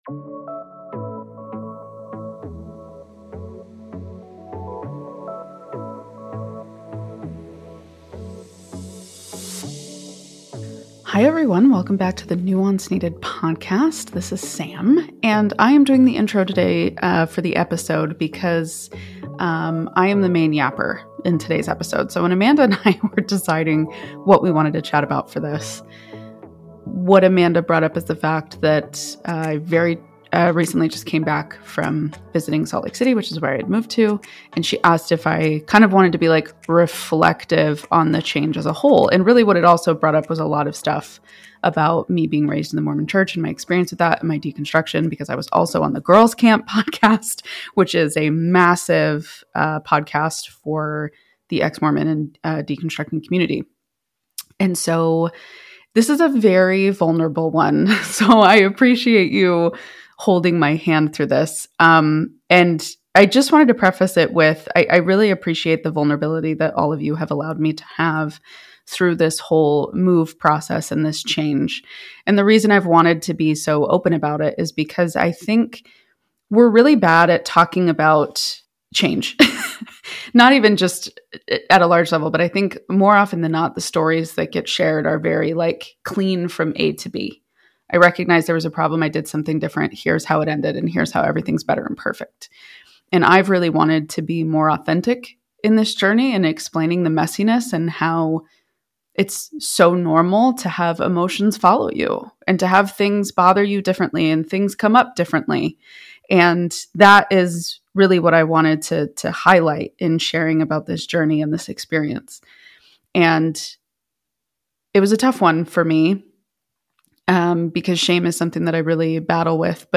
This candid conversation sheds light on the subtle ways high-demand religious cultures can shape identity and limit personal growth, even when you're trying to carve your own path within them.